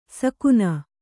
♪ sakuna